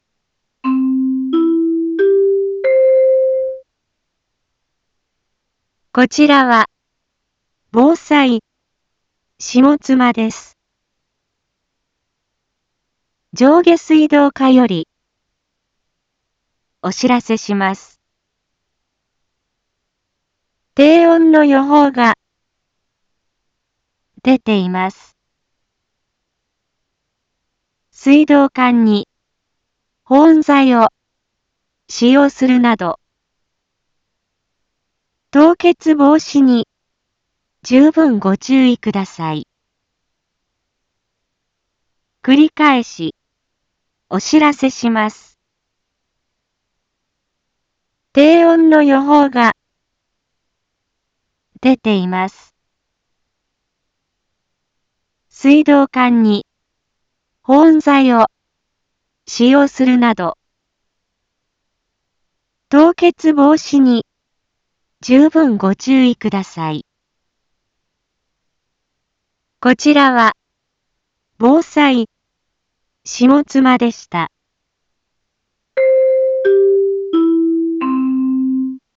一般放送情報
Back Home 一般放送情報 音声放送 再生 一般放送情報 登録日時：2022-01-07 16:46:22 タイトル：凍結防止対策のお願いについて インフォメーション：こちらは、防災下妻です。